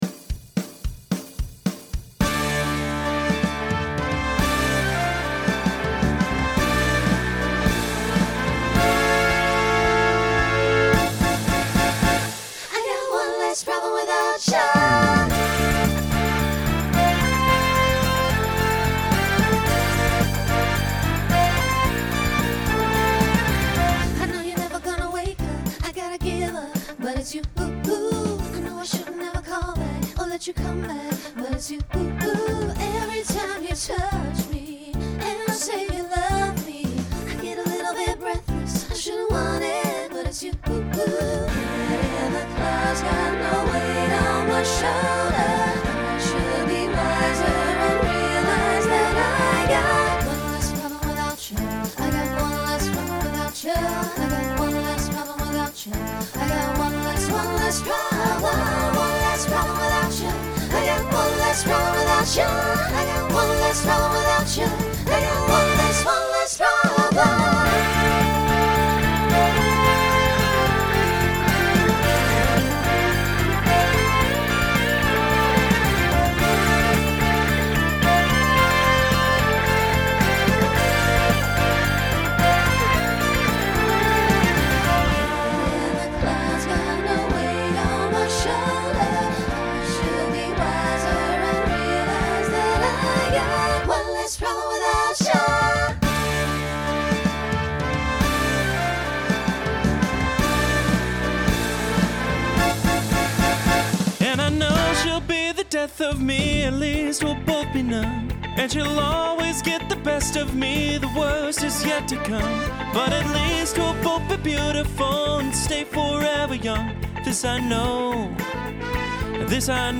followed by an SATB overlap.
Pop/Dance
Instrumental combo
Voicing Mixed